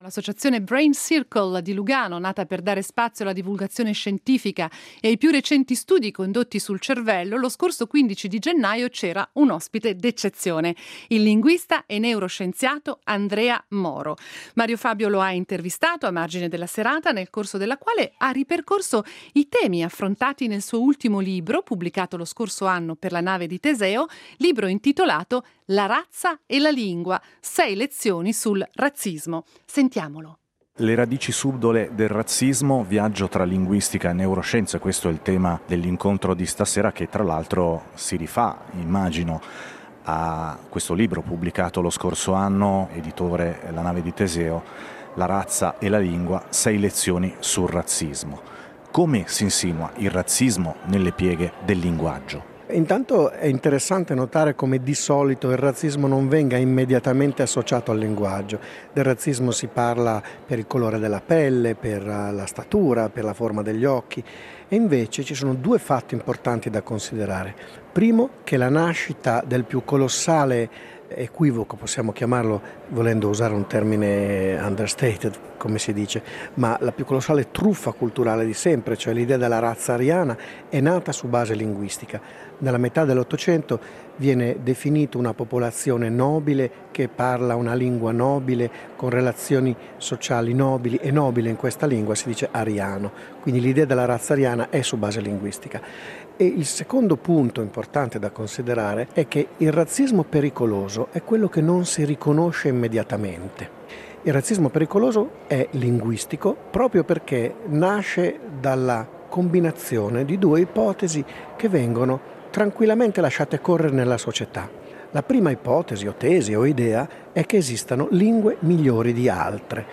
Intervista ad Andrea Moro